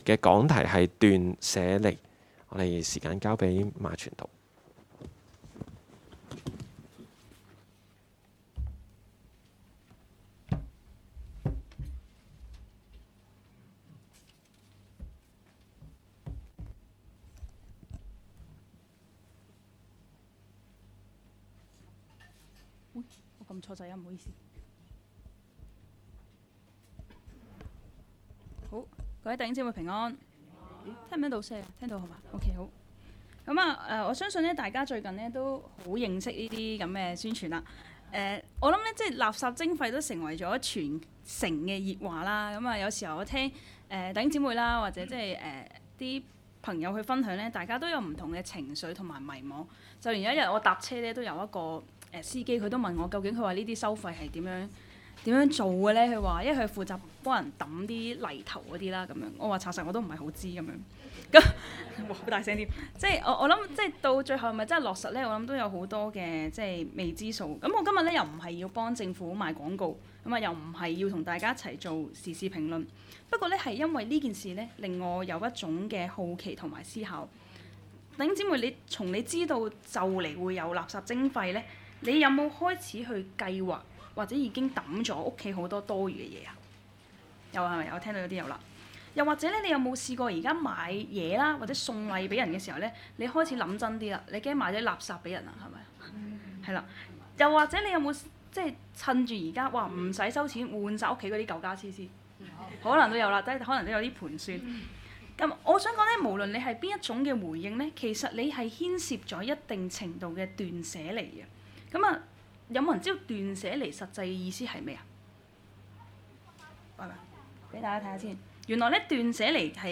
講道 ： 斷捨離